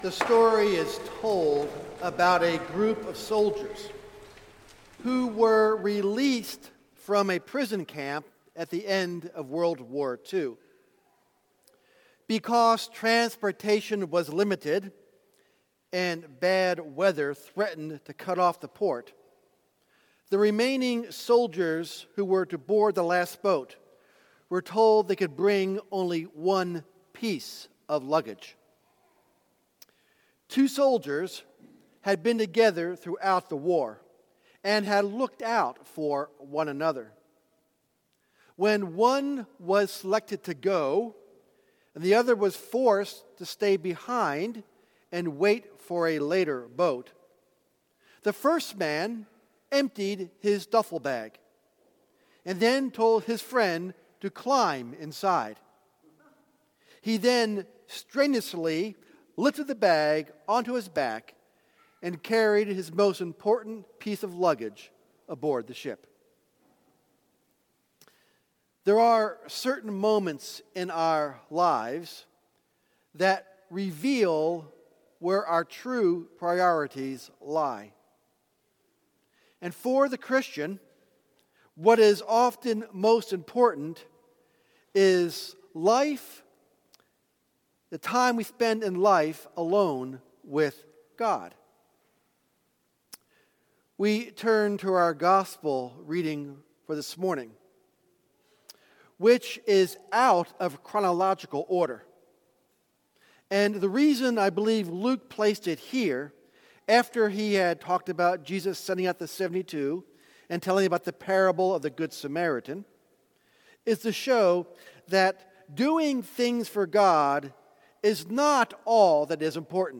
Sermon for Sixth Sunday after Pentecost